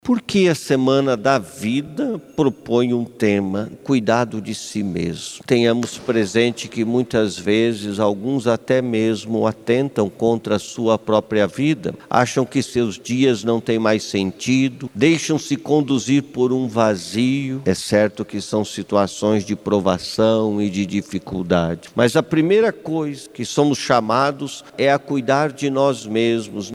Durante sua homilia, Dom Reginei Modolo enfatizou que a vida é um bem inestimável, mesmo diante de circunstâncias adversas ou difíceis de compreender.